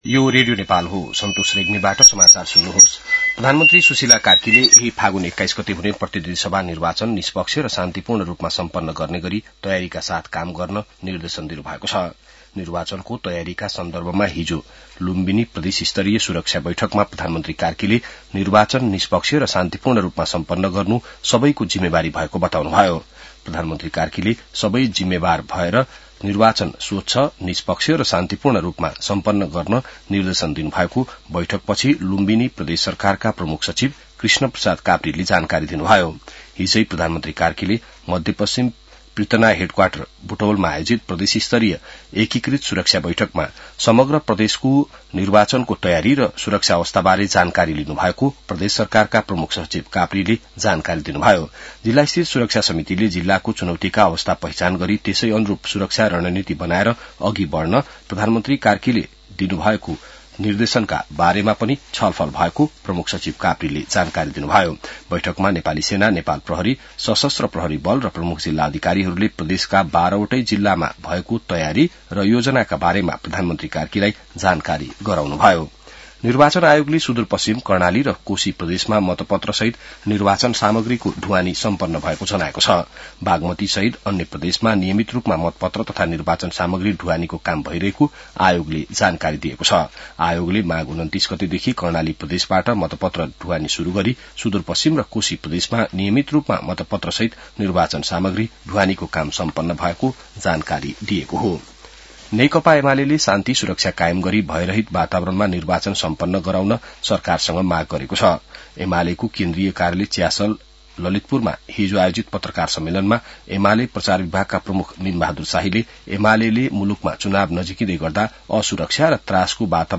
बिहान ६ बजेको नेपाली समाचार : १० फागुन , २०८२